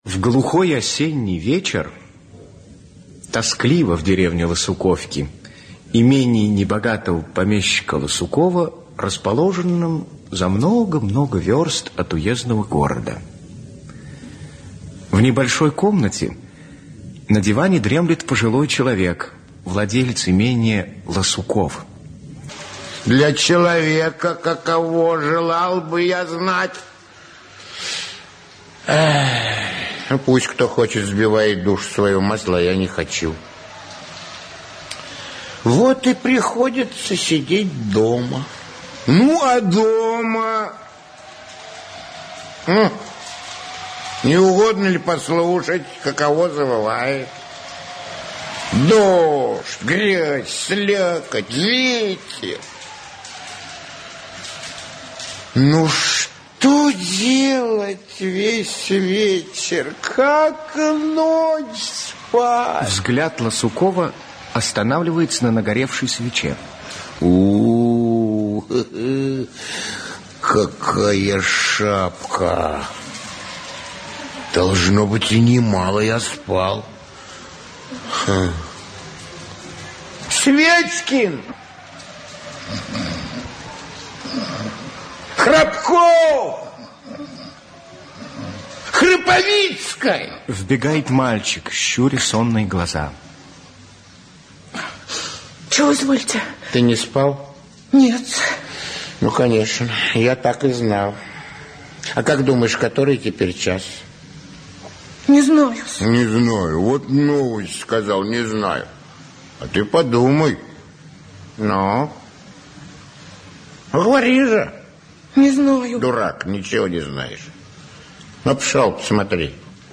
Аудиокнига Осенняя скука (спектакль) | Библиотека аудиокниг
Aудиокнига Осенняя скука (спектакль) Автор Николай Некрасов Читает аудиокнигу Алексей Грибов.